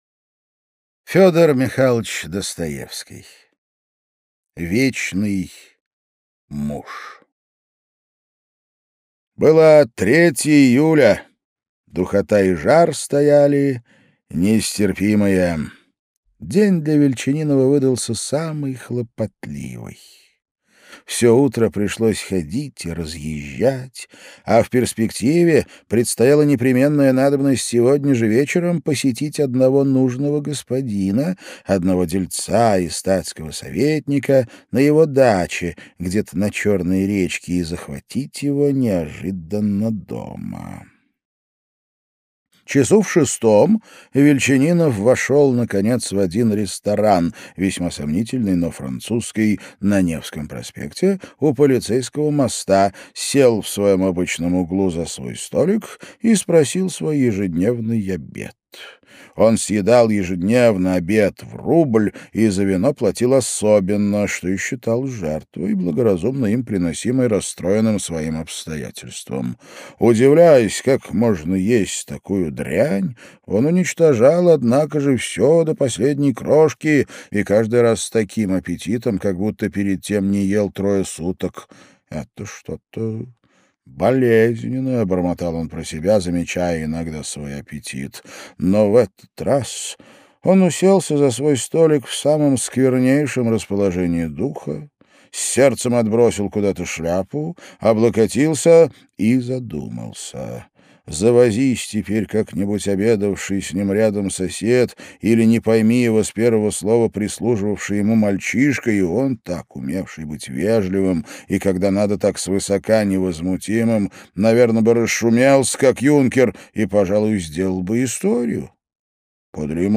Aудиокнига Вечный муж